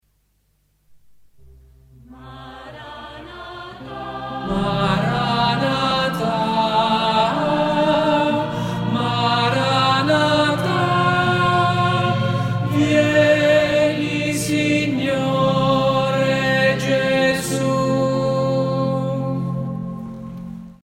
tenore.mp3